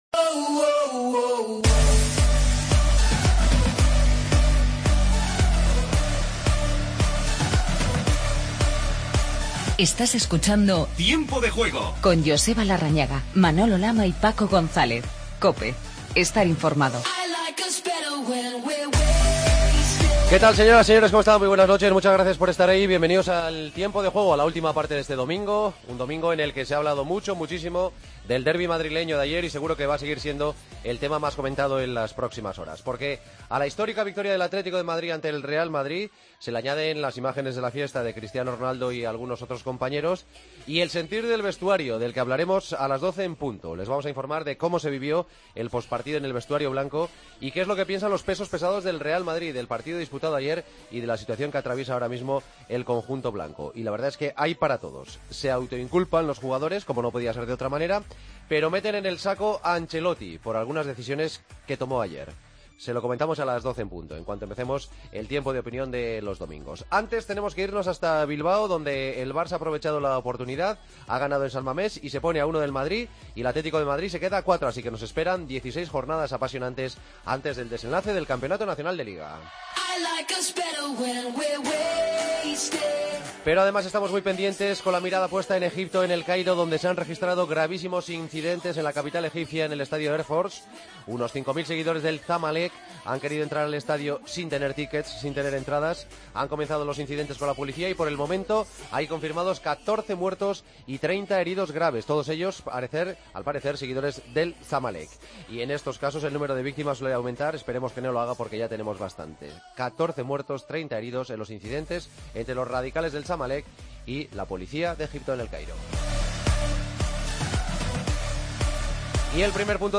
Paso por San Mamés para escuchar a los protagonistas de la victoria del Barça sobre el Athletic. Escuchamos en zona mixta a Xavi y Neymar. Entrevista a Mikel Rico.